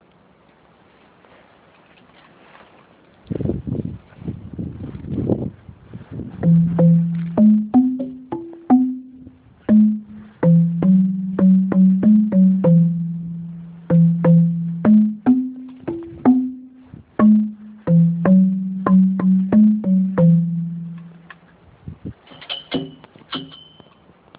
Frauchen konnte es sich natürlich nicht verkneifen den Musiktieren eine kleine Melodie zu entlocken und nachdem auch Ais gezeigt hatte, daß sie einem Musikerhaushalt entstammt, war die Bühne frei für folgende Darbietung, die weithin durch den Wald schallte:
Xylophon
Xylophon.amr